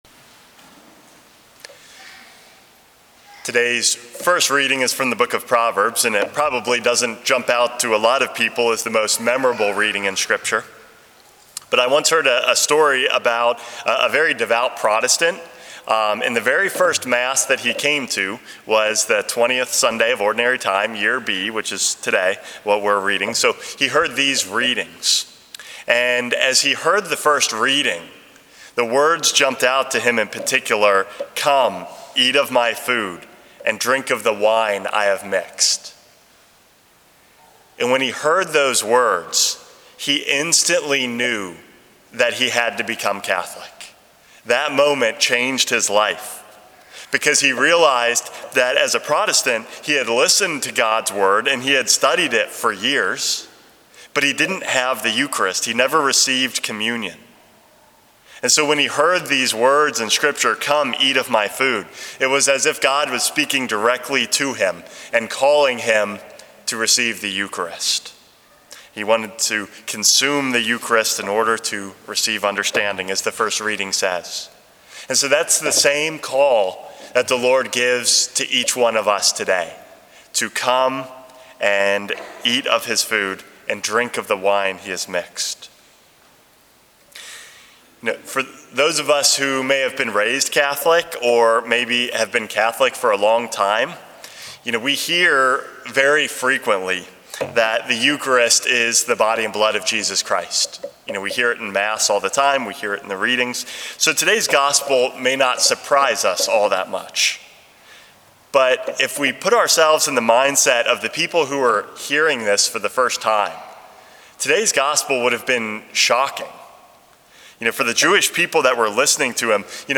Homily #410 - Life in Christ's Blood